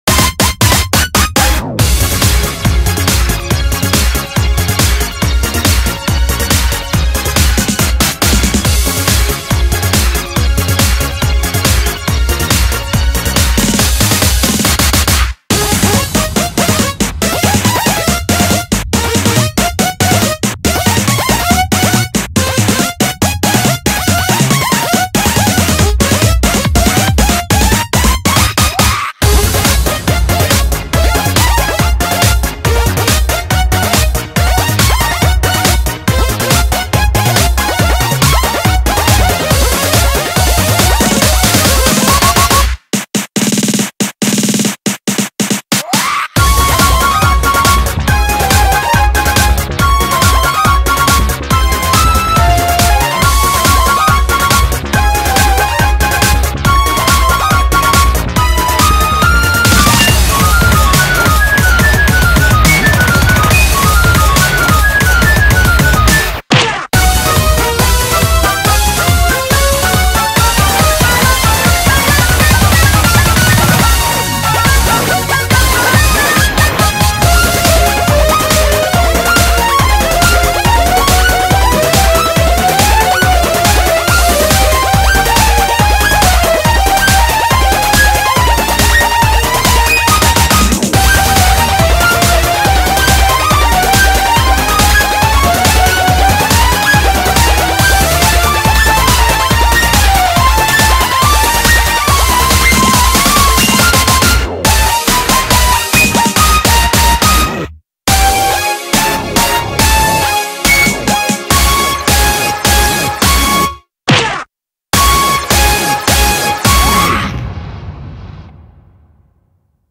BPM53-140